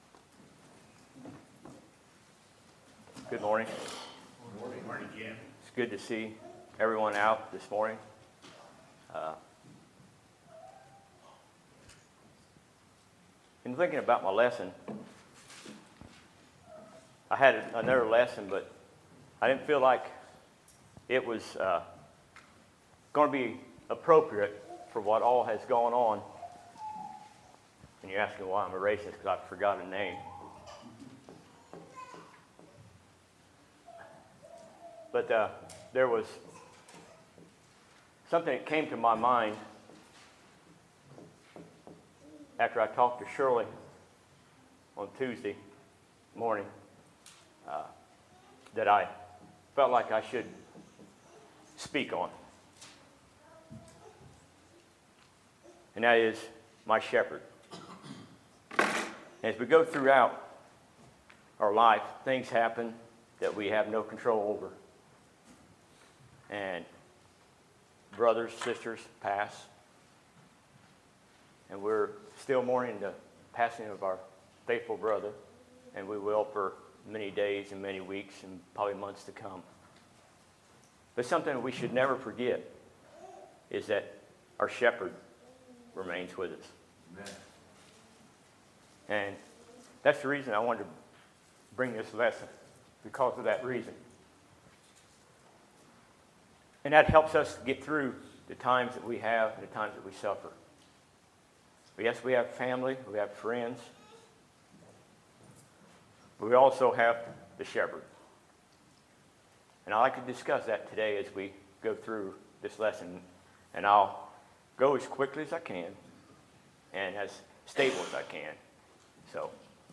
Sermons, February 4, 2018